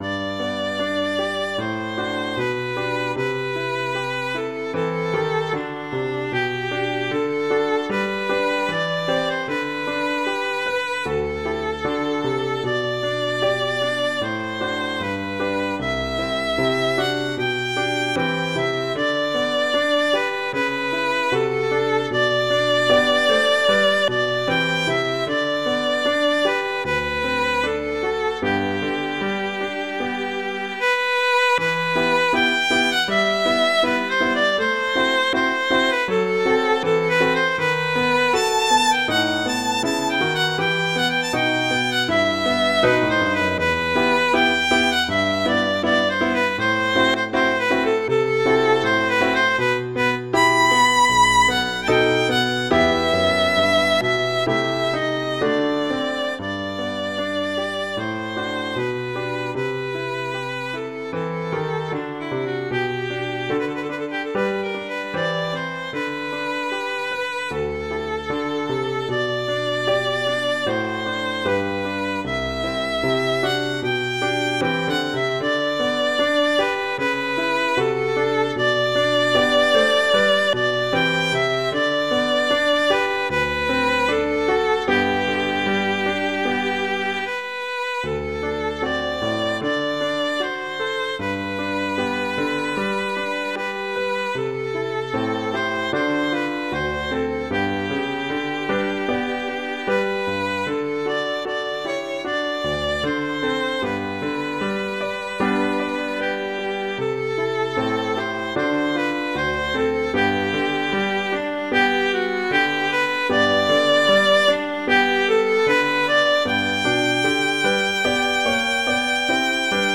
classical
♩=76 BPM